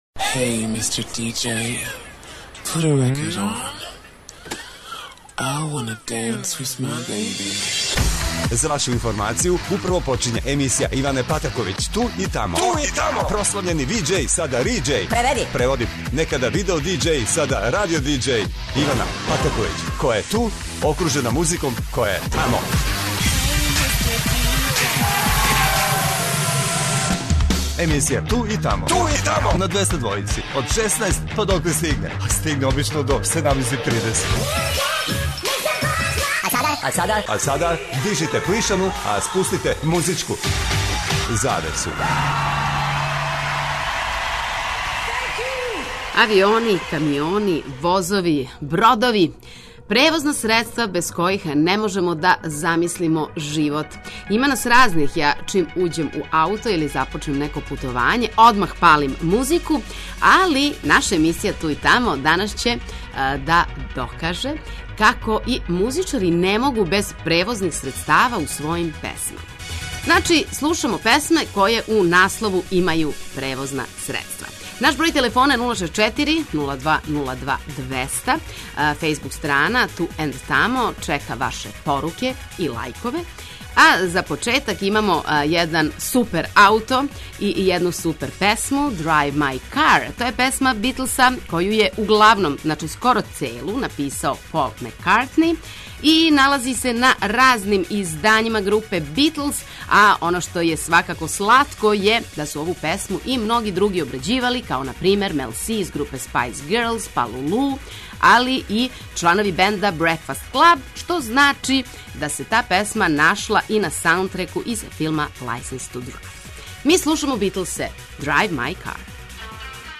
На програму најновије емисије "Ту и тамо" су домаће и стране песме које у наслову имају превозна средства.